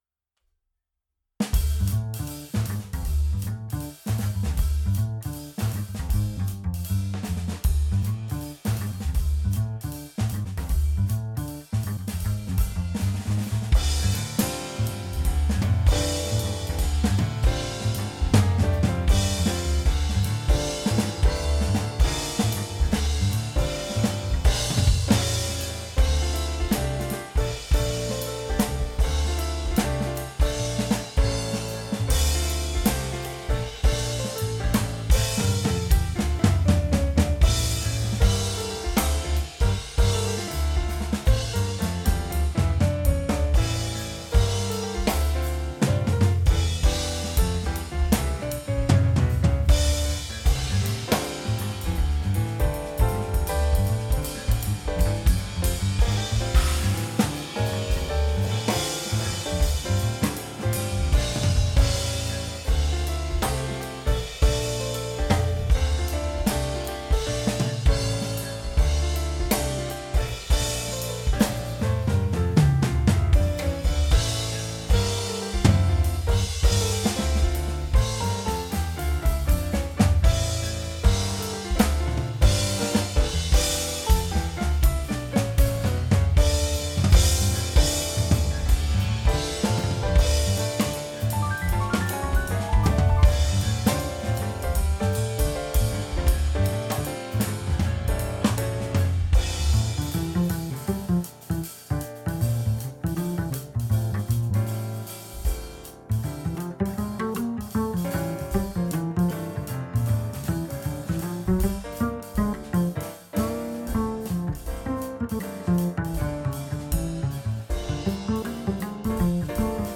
Просто разное сведение. hola!